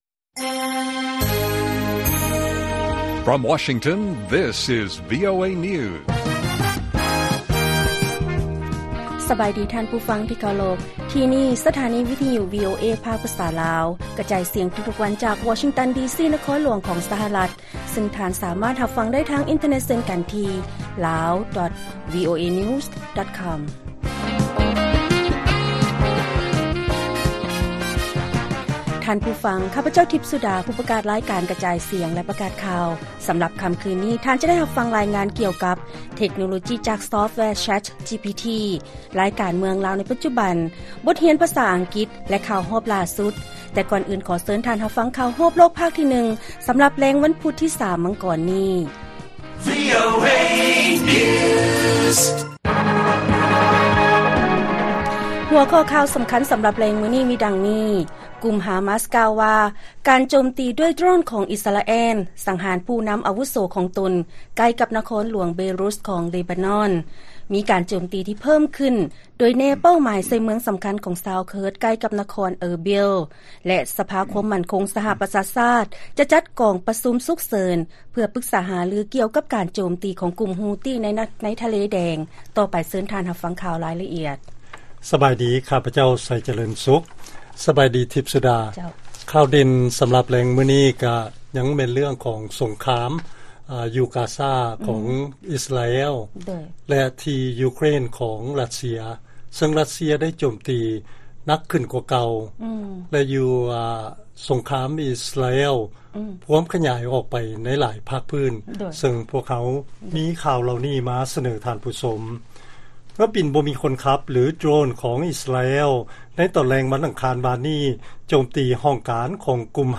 ລາຍການກະຈາຍສຽງຂອງວີໂອເອ ລາວ: ກຸ່ມຮາມາສກ່າວວ່າ ການໂຈມຕີດ້ວຍໂດຣນ ຂອງອິສຣາແອລ ສັງຫານຜູ້ນຳອະວຸໂສຂອງຕົນ ໃກ້ກັບນະຄອນຫຼວງເບຣຸດ ຂອງເລບານອນ